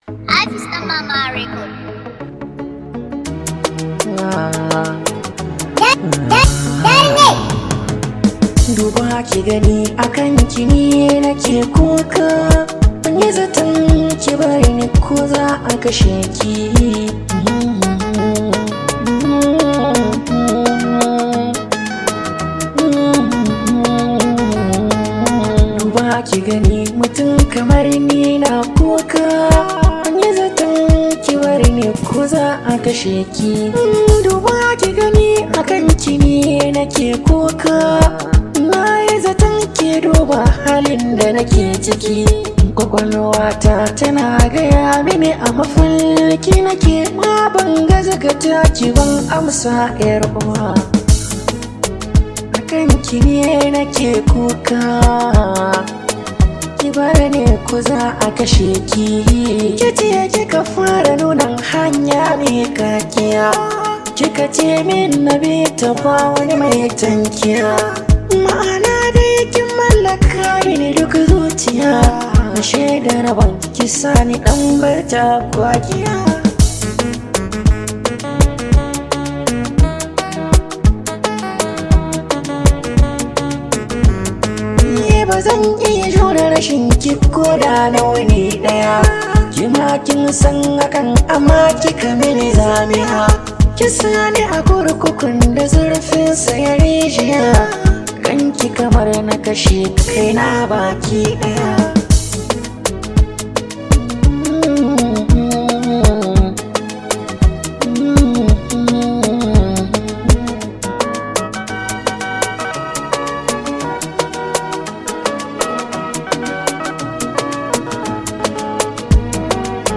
hausa song
This high vibe hausa song